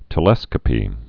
(tə-lĕskə-pē)